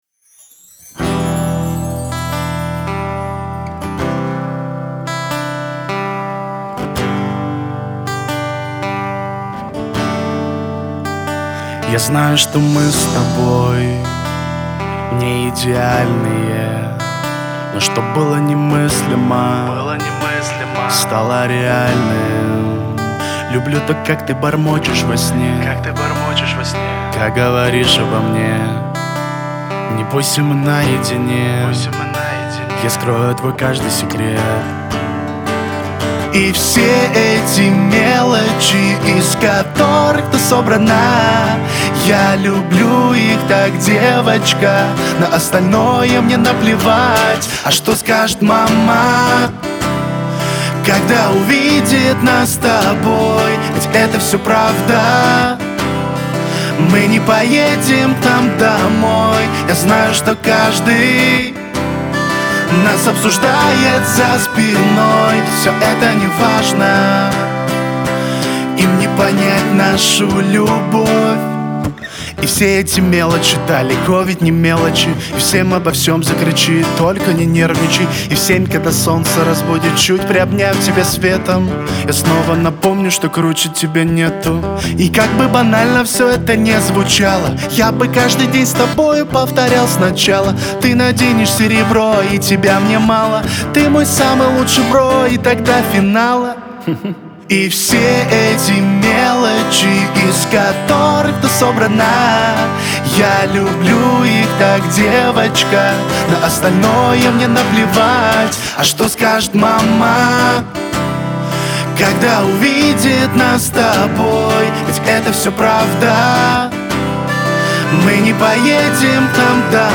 Певческий голос
Тенор